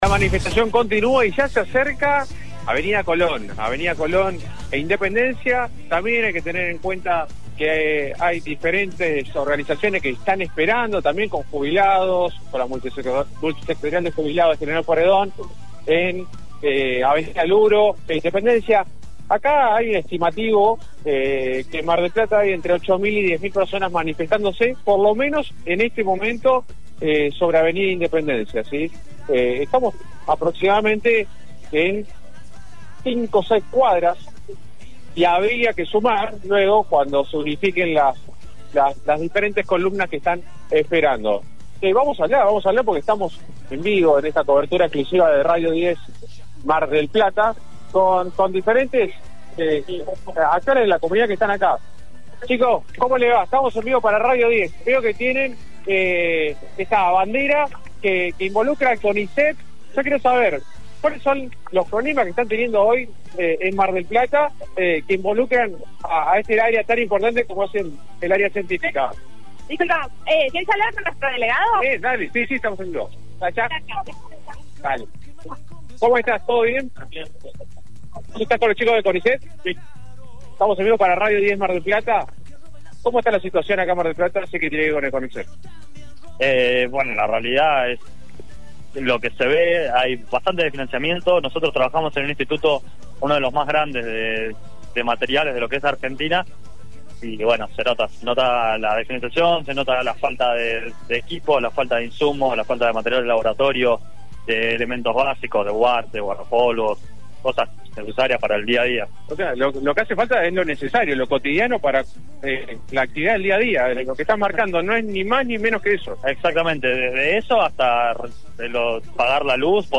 Radio 10 Mar del Plata transmitió en vivo la movilización que recorrió el centro de la ciudad con sindicatos, estudiantes, jubilados y organizaciones sociales.